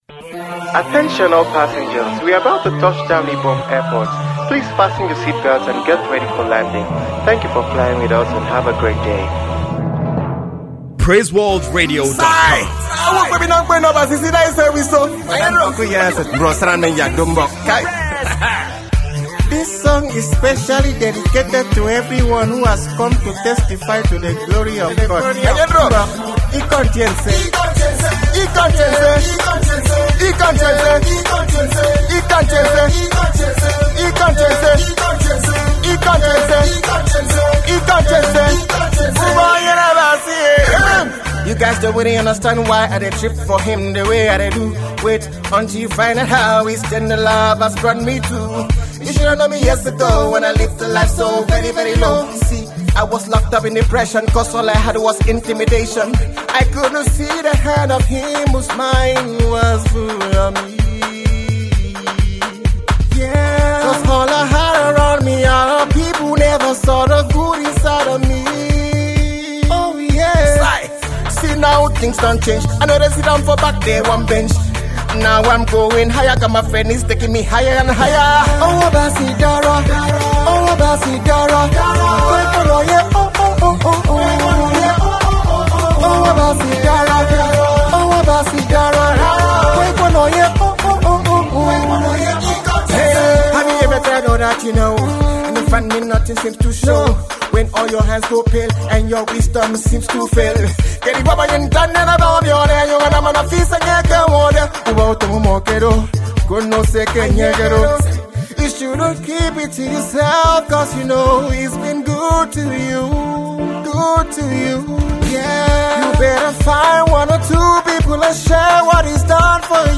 gospel
free instrumentals